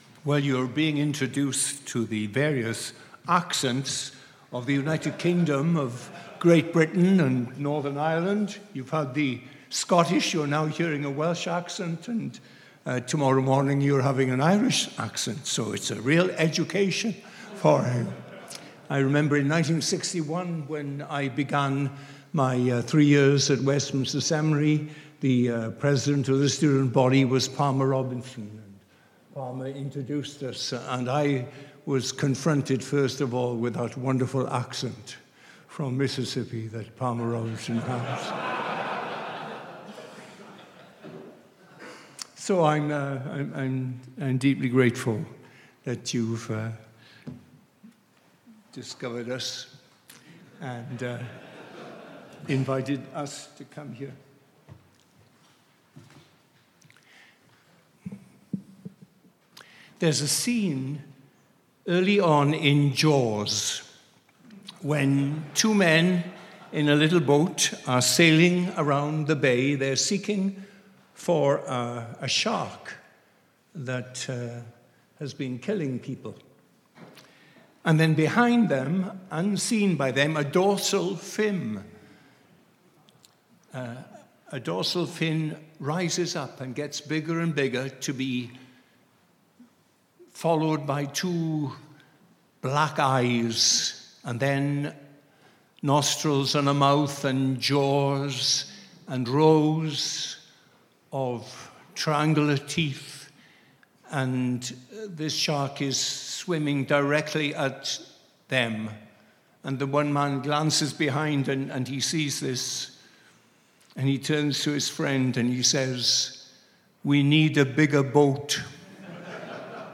Worship Service 1 – The Immensity of the Love